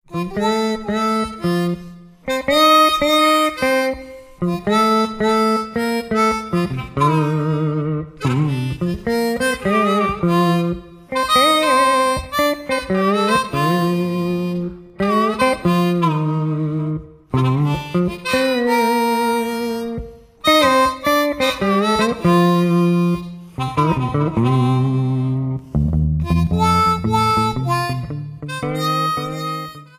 Lap Slide Steel Guitar
Harmonica
A lap slide guitar and a harmonica.
an instrumental blues duet